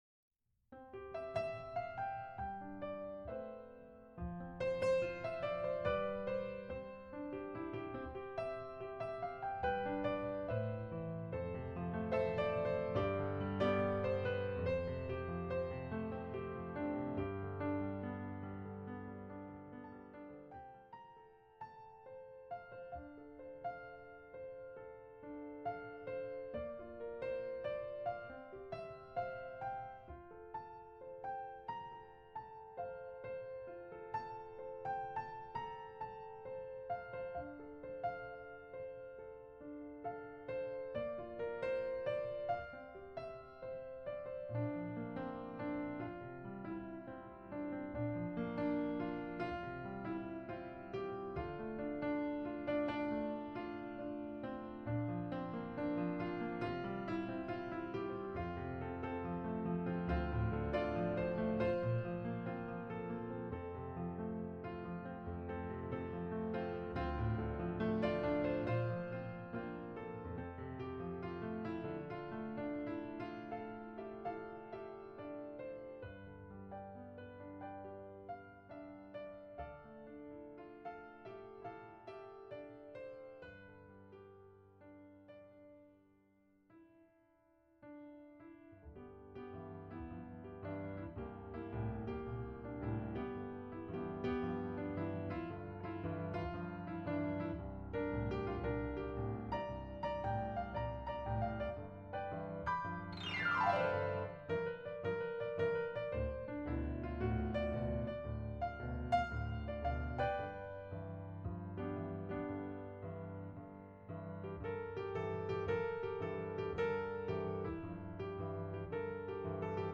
Preview MP3 medley mix containing clips